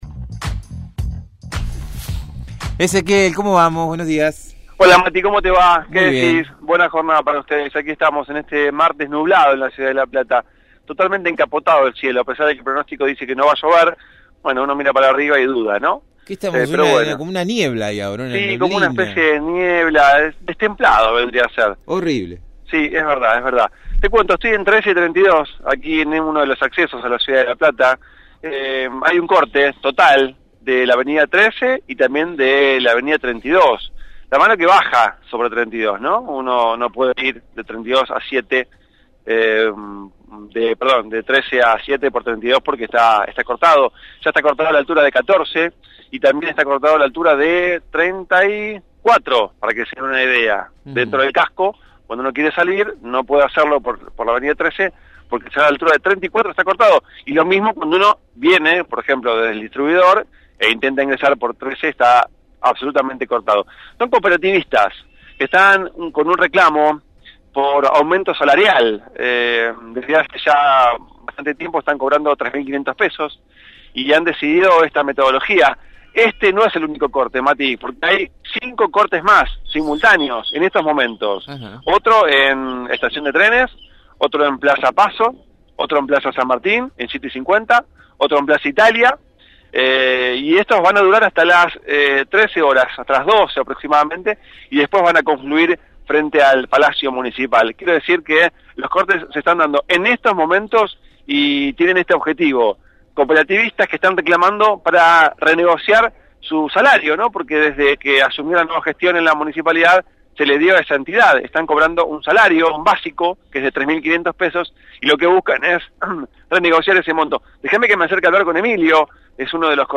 MÓVIL/ Cortes simultáneos de cooperativistas por reclamos salariales – Radio Universidad